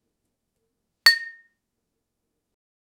Gentle clinking of glass bottles in a 19th-century pharmacy.
gentle-clinking-of-glass--2kl3urlr.wav